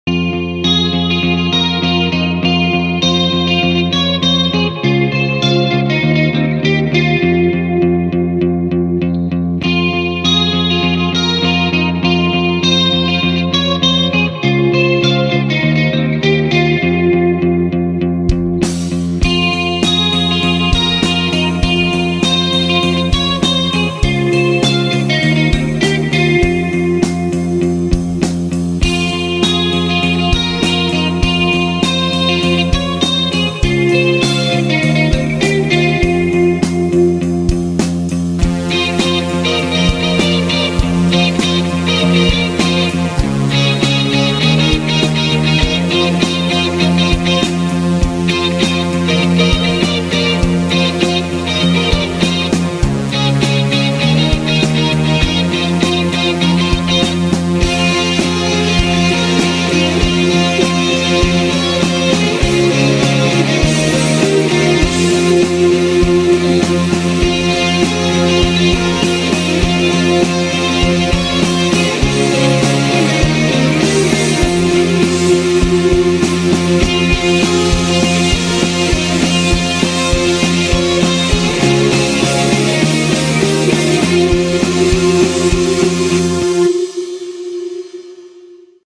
ورژن گیتار